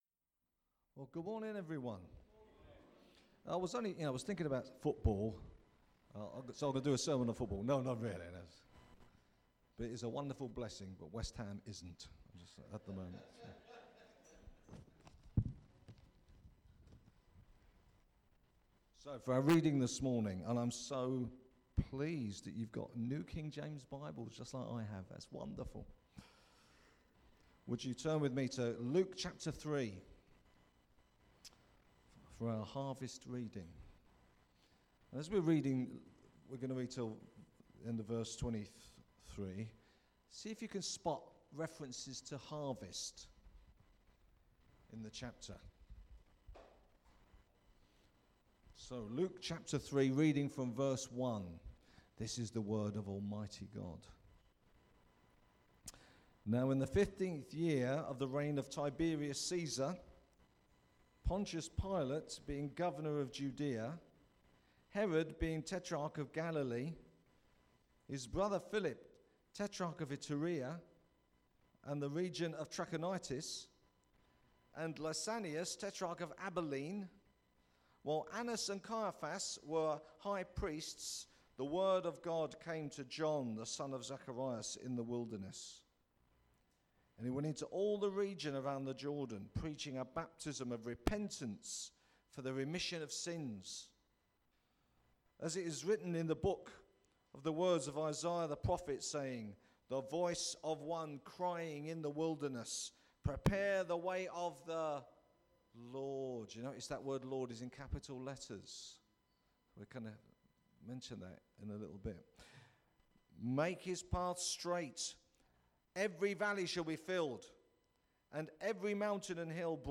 A Sunday sermon by guest speaker